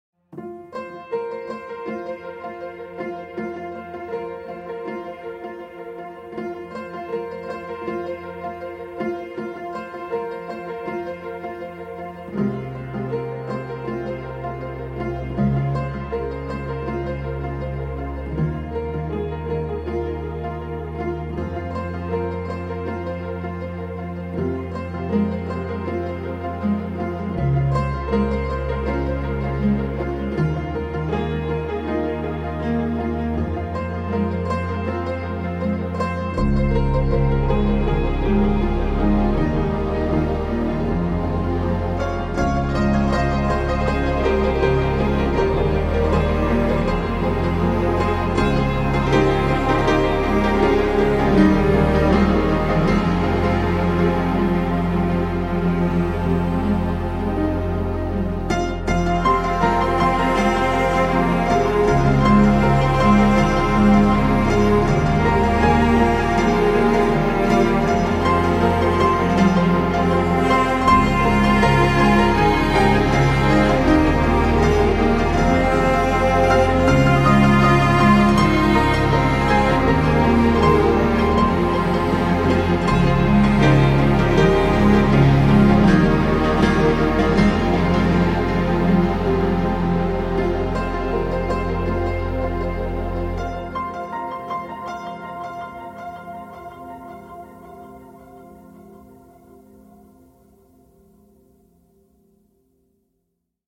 Soundiron Sunroom Upright Piano 是一款基于 Kontakt 的虚拟钢琴音源，它采样了一架 1980 年代的 Conn 立式钢琴，拥有温暖、浑厚和木质的声音和氛围。这款音源不仅提供了原始的钢琴音色，还包含了由钢琴声音加工而成的多种环境垫音、演变无人机、冲击音效等，适合用于创造性的音乐制作和声音设计。
- 多采样的钢琴音符，包括 9 个力度层、6 个轮换、释放音、簇音、滑音、无音键和踏板噪音
Soundiron-Sunroom-Upright-Piano.mp3